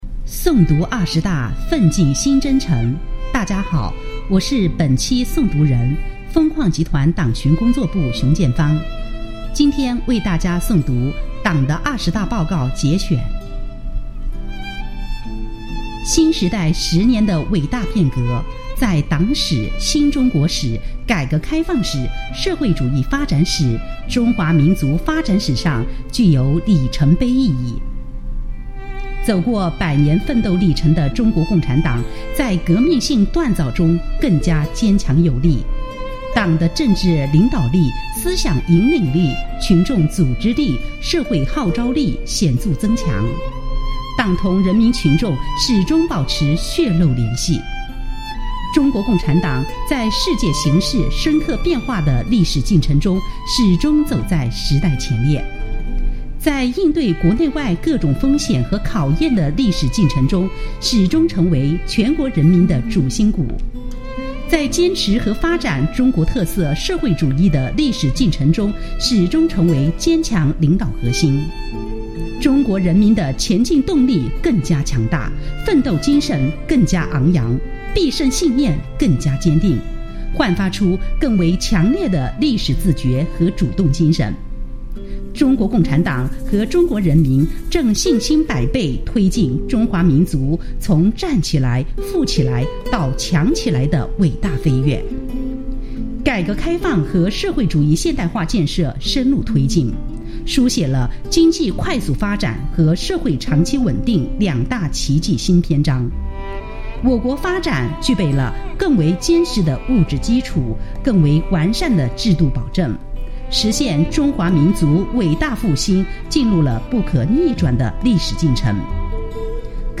诵读党的二十大报告（一）